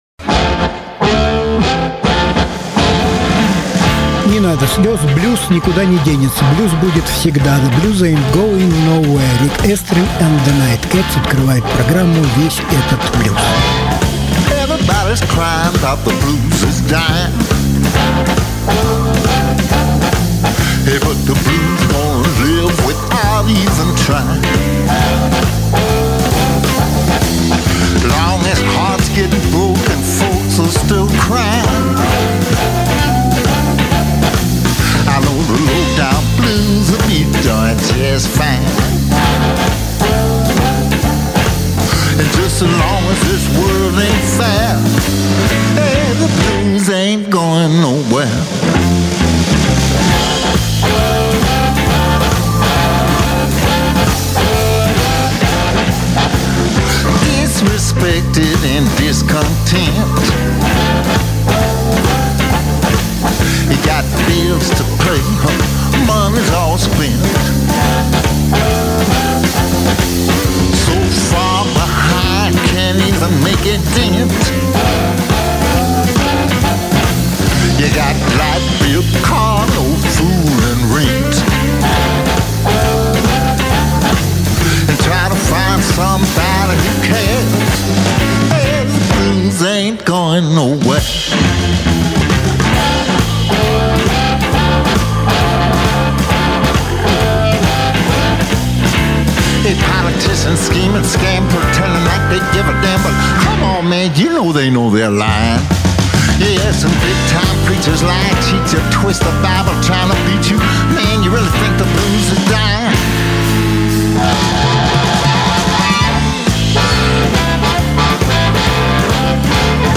Жанр: Блюз